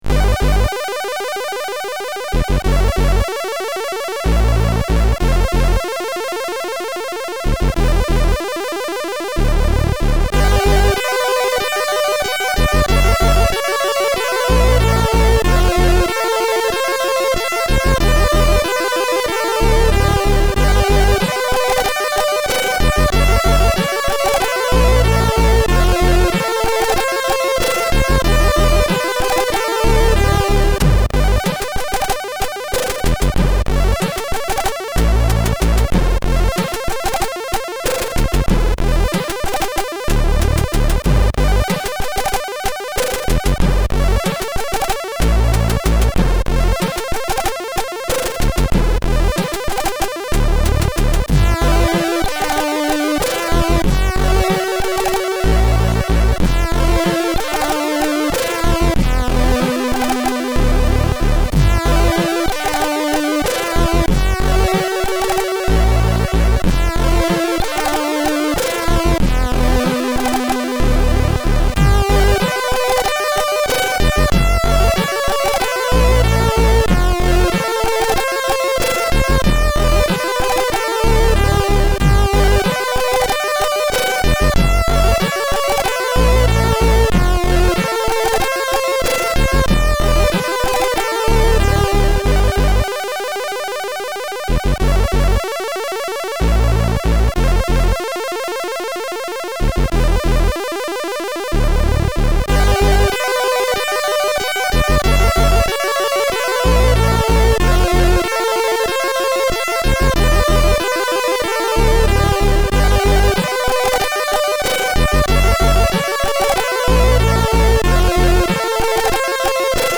Bassy and cool.